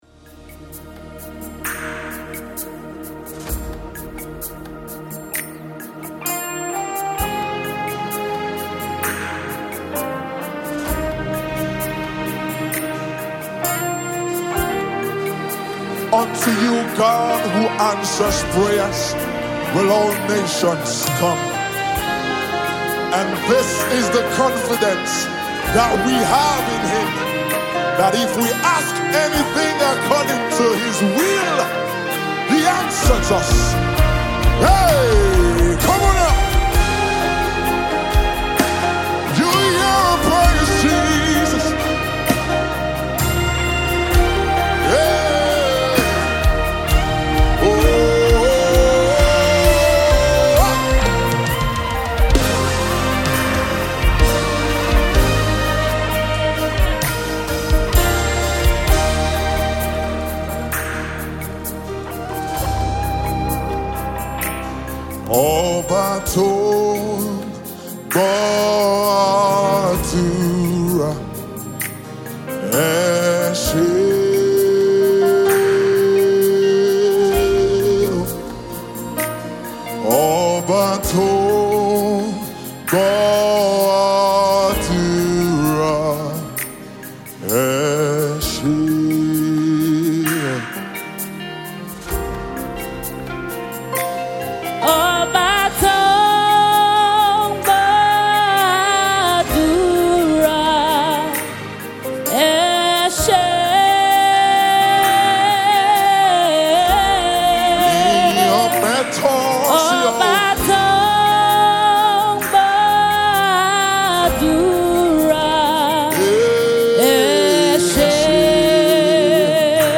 soul-lifting new Thanksgiving single